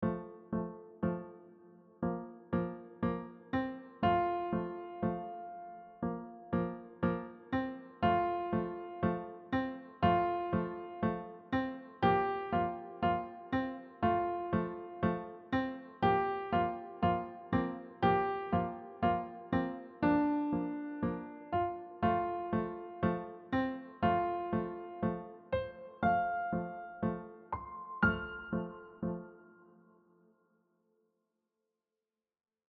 Key: F Major with accidentals
Time Signature: 4/4 (march style)
BPM: ≈ 120
• Steady left-hand beat with crisp staccatos